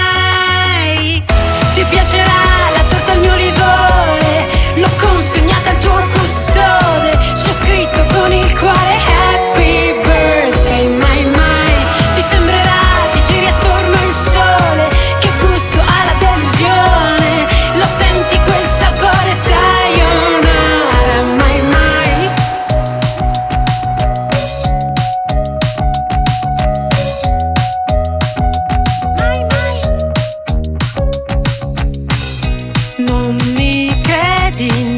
Pop
2025-06-06 Жанр: Поп музыка Длительность